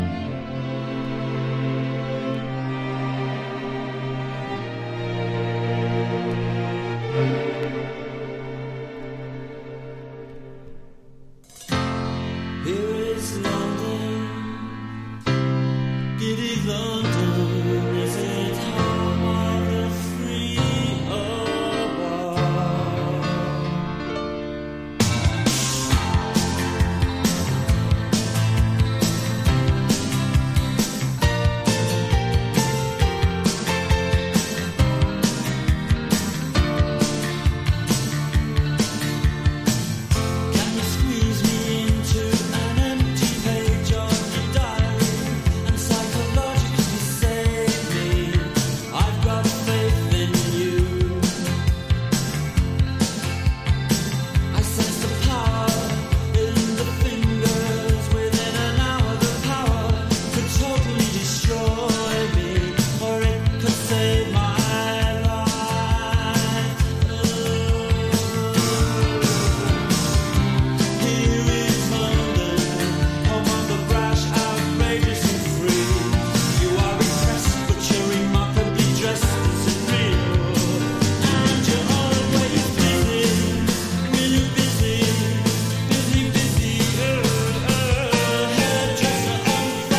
NEO ACOUSTIC / GUITAR POP# NEW WAVE# 80’s ROCK / POPS